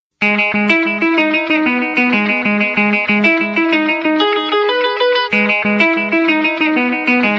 Genere : Pop punk